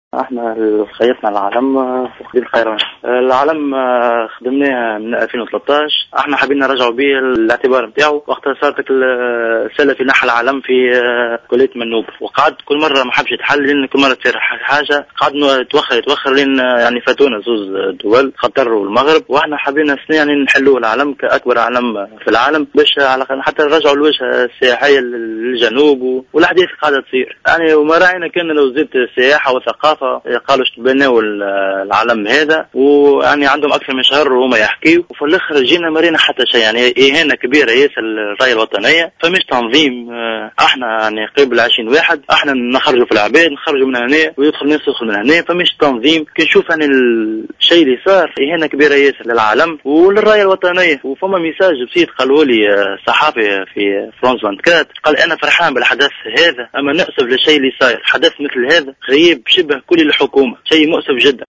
في تصريح لجوهرة أف أم